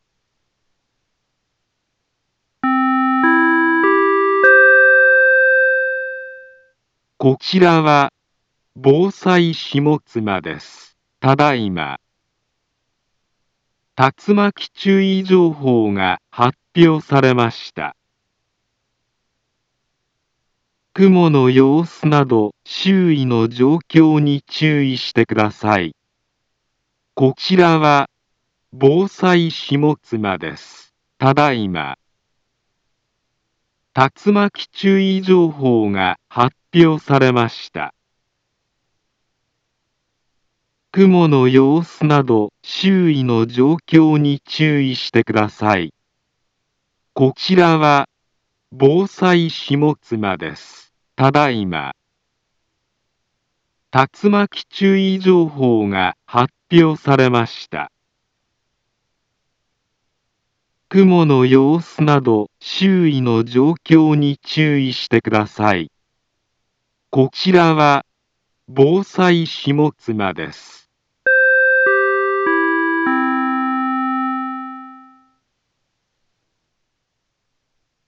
Back Home Ｊアラート情報 音声放送 再生 災害情報 カテゴリ：J-ALERT 登録日時：2023-06-28 16:15:12 インフォメーション：茨城県南部は、竜巻などの激しい突風が発生しやすい気象状況になっています。